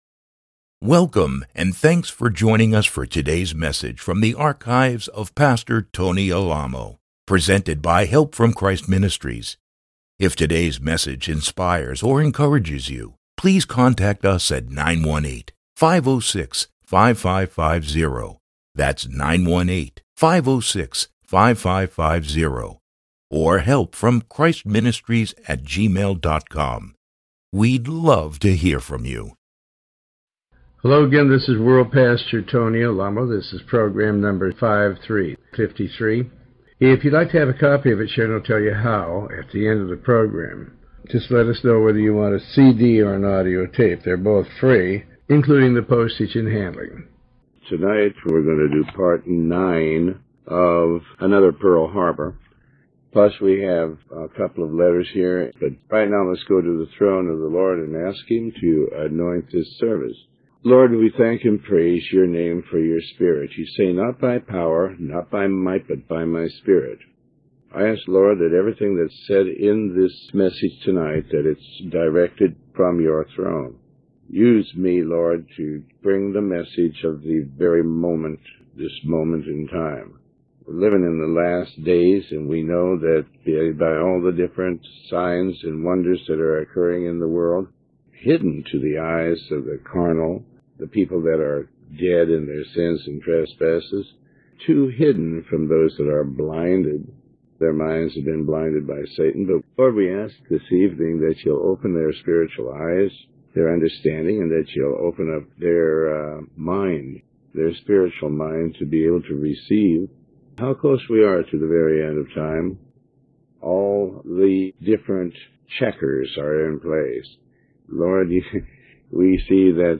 Tony Alamo Sermon